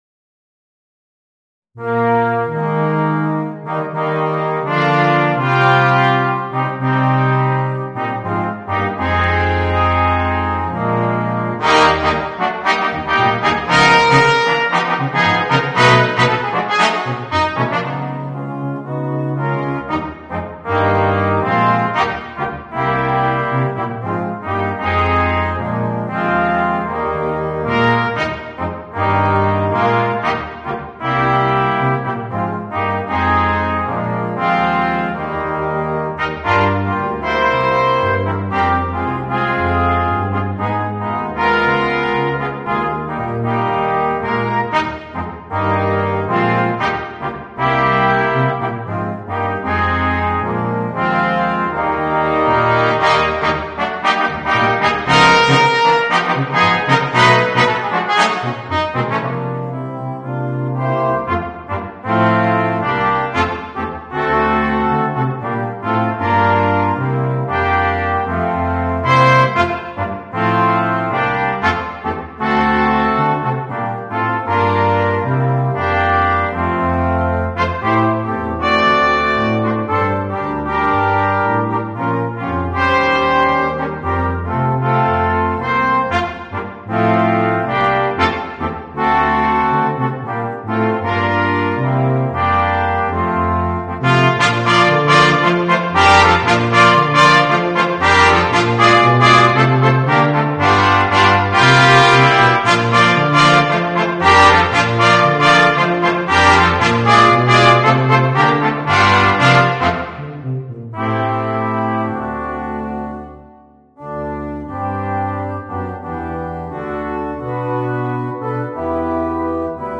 Voicing: 2 Trumpets, Horn, Trombone and Tuba